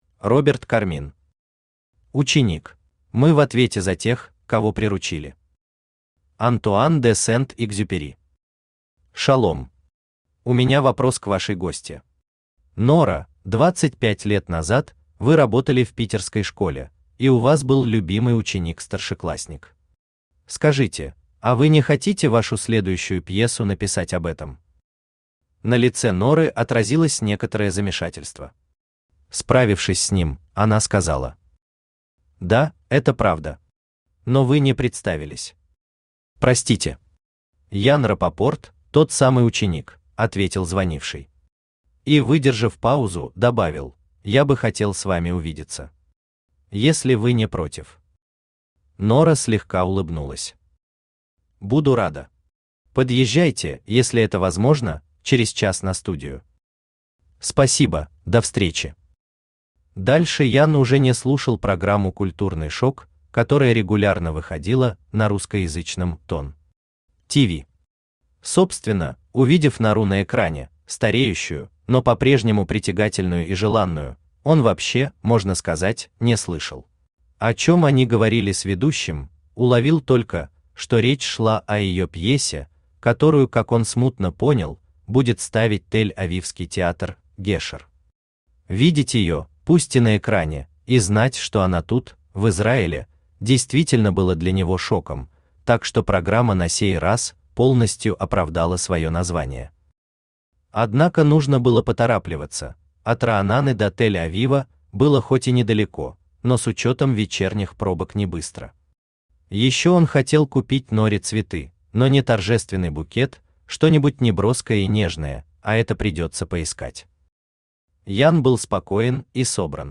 Аудиокнига Ученик | Библиотека аудиокниг
Aудиокнига Ученик Автор Роберт Кармин Читает аудиокнигу Авточтец ЛитРес.